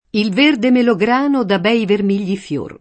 il v%rde melogr#no da b$i verm&l’l’i fL1r] (Carducci); i melagrani vogliono essere ogni anno potati, e netti [